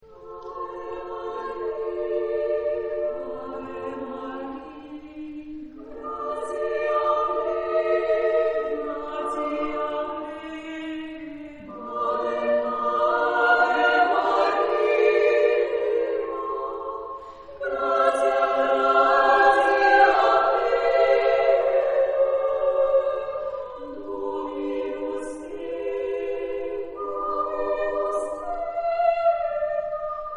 Género/Estilo/Forma: Motete ; Sagrado
Tipo de formación coral: SSAA  (4 voces Coro femenino )
Tonalidad : libre